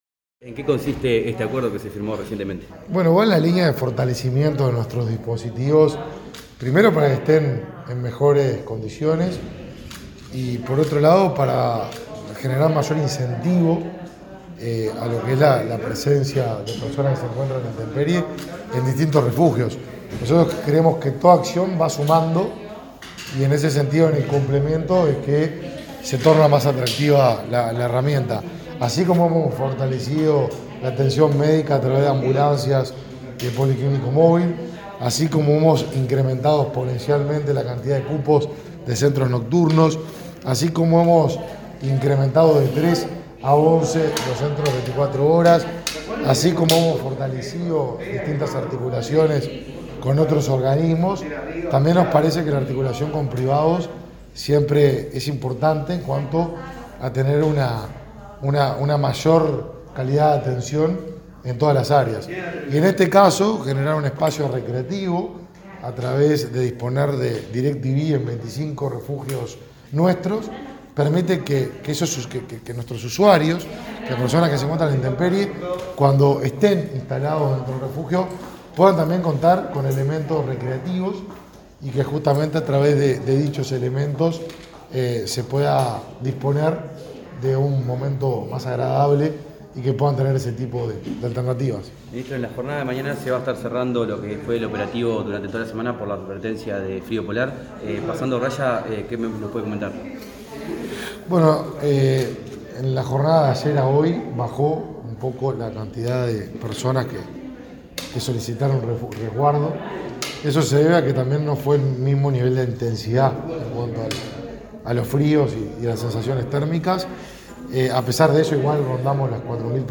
Declaraciones del ministro de Desarrollo Social, Martín Lema